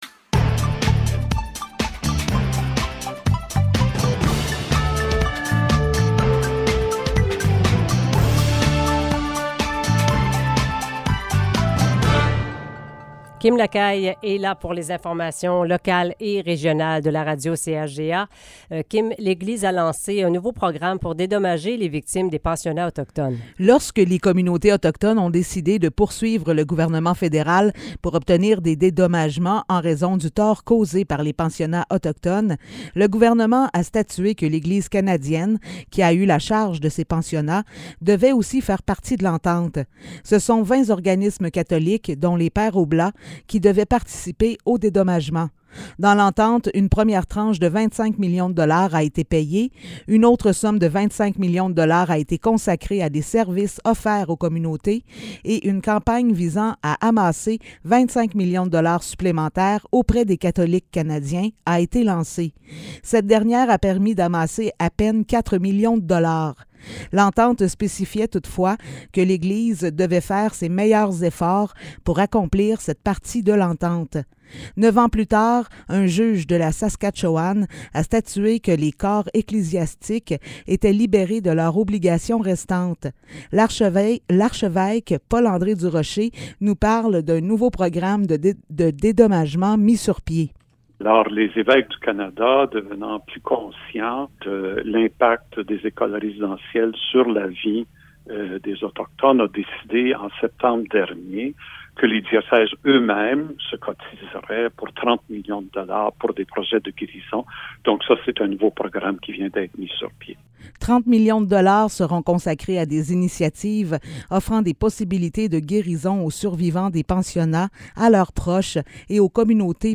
Nouvelles locales - 20 juillet 2022 - 16 h